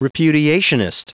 Prononciation du mot repudiationist en anglais (fichier audio)
Prononciation du mot : repudiationist